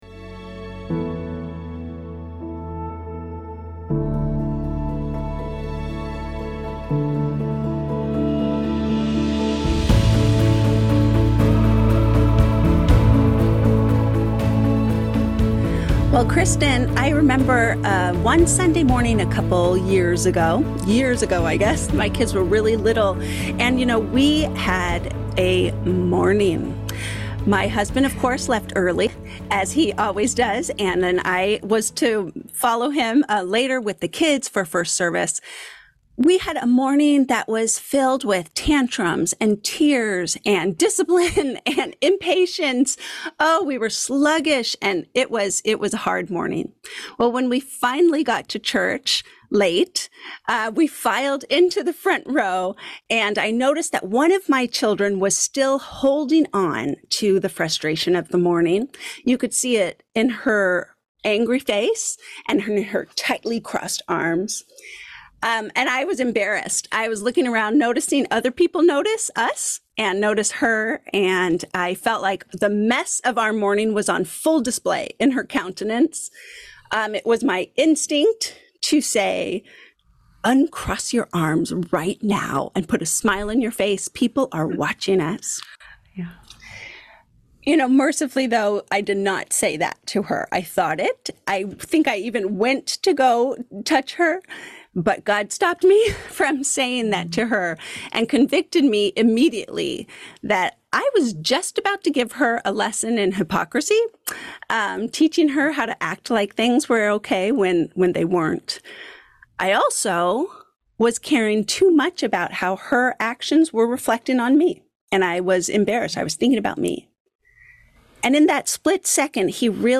Whatever season of parenting you’re in as a pastor’s wife, this conversation is for you.